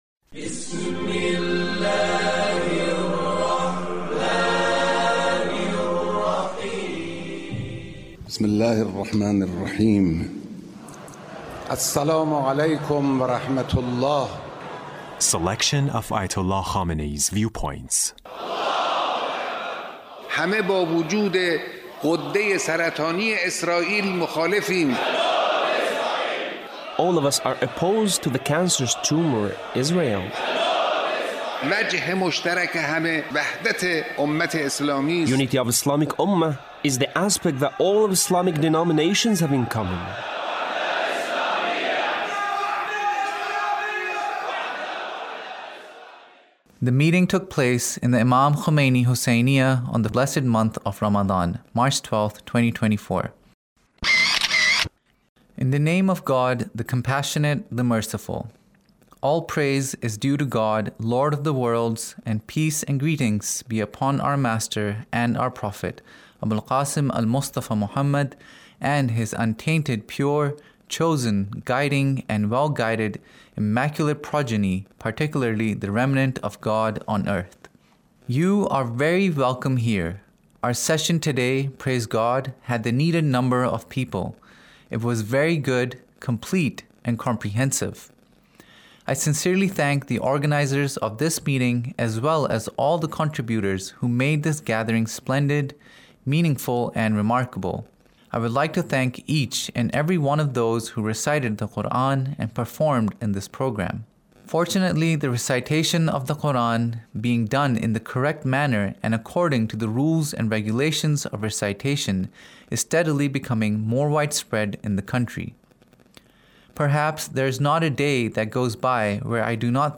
Leader's Speech Of The Quranic Reciters